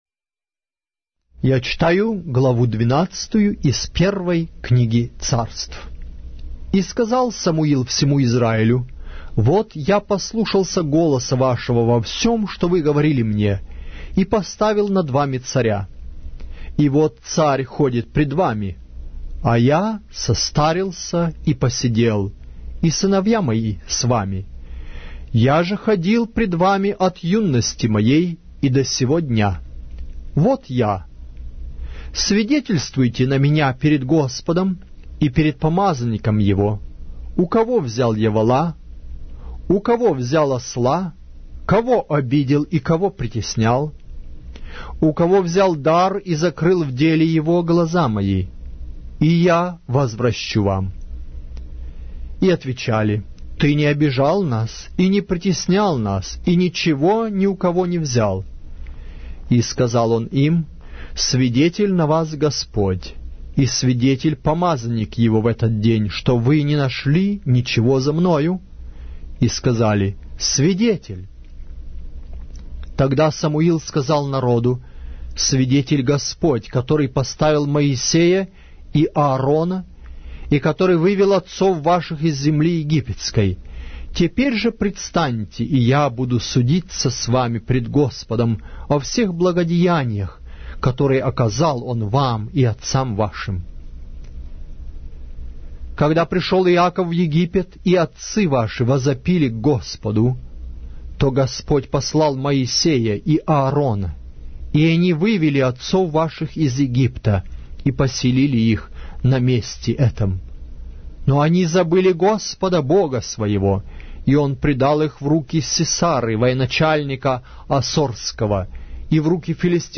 Глава русской Библии с аудио повествования - 1 Samuel, chapter 12 of the Holy Bible in Russian language